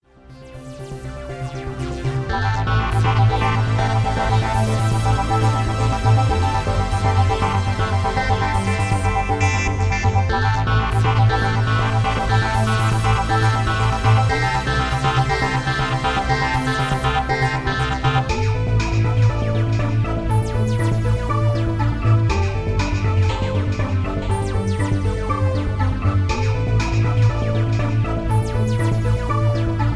Electro Ambient with feel of tension